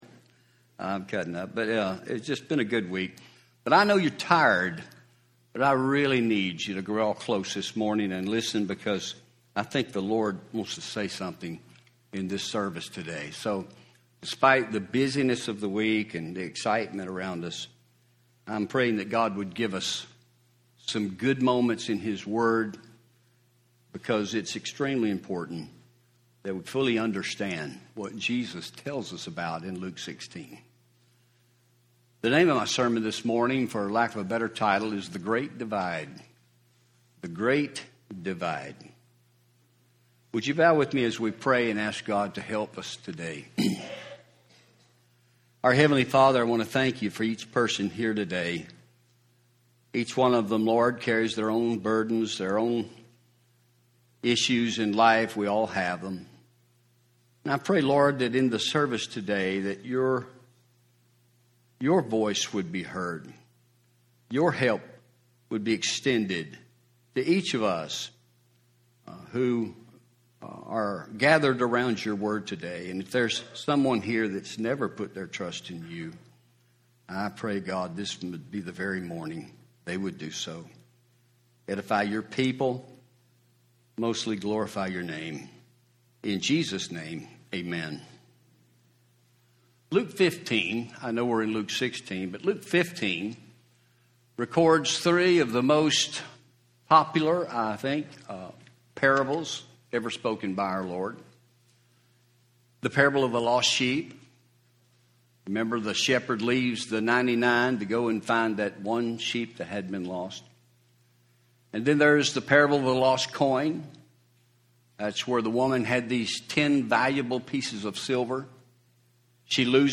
Home › Sermons › The Great Divide